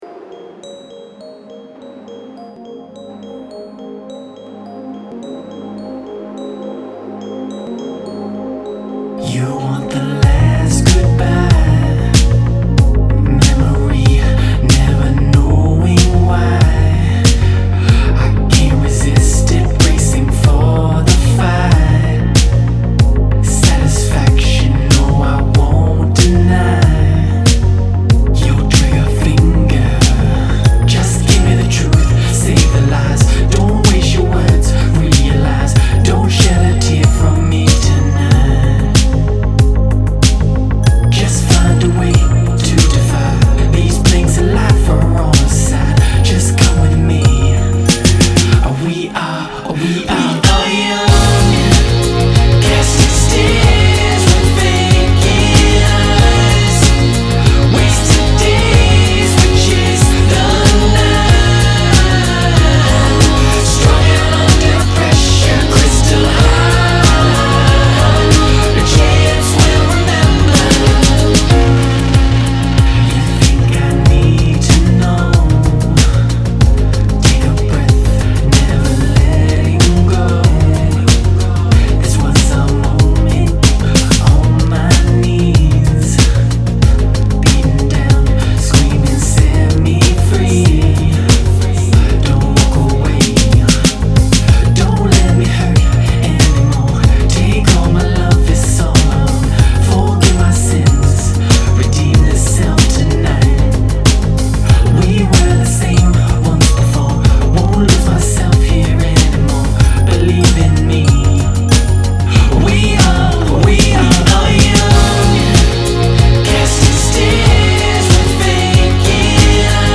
irresistible chillwave, w/ guitar solo